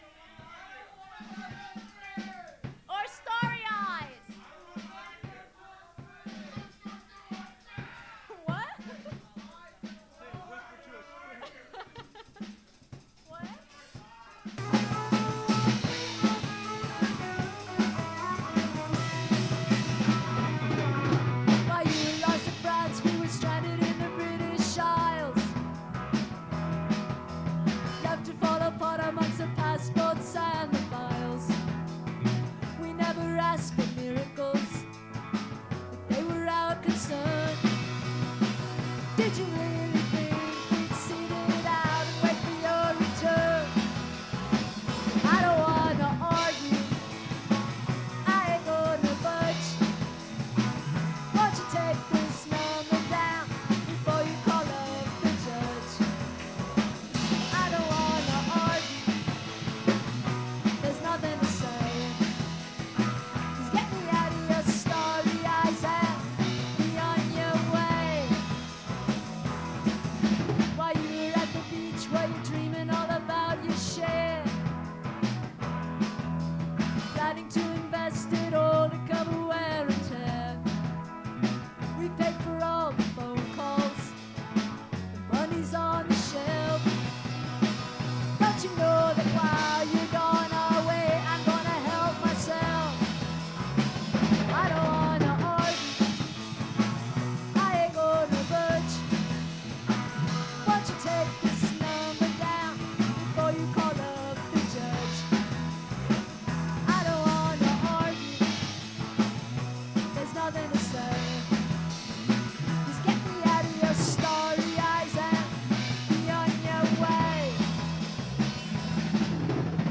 with a very difficult guitar reiff